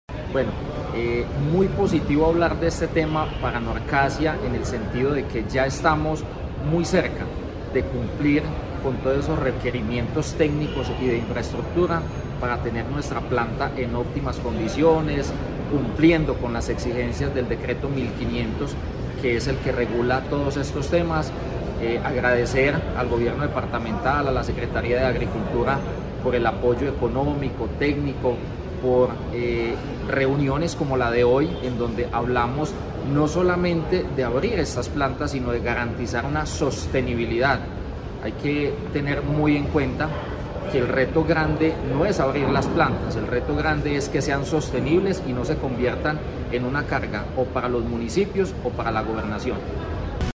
Diego Fernando Olarte, alcalde de Norcasia.